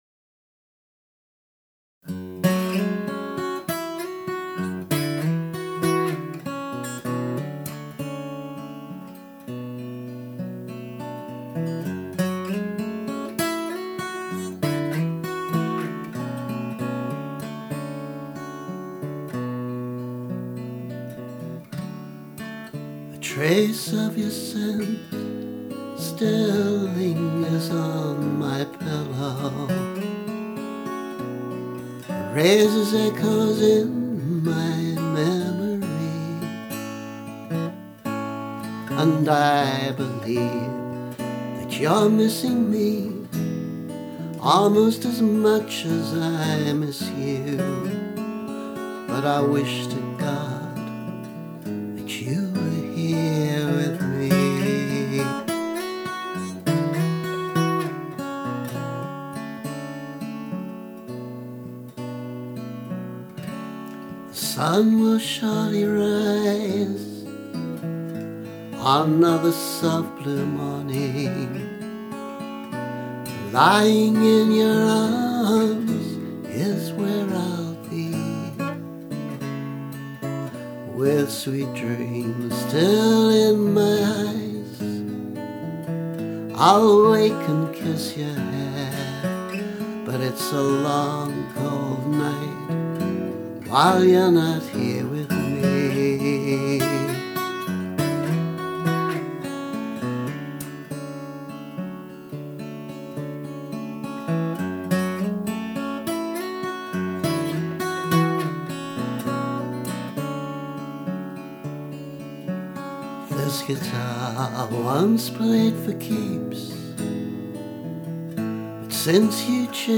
This guitar just plays the blues [demo]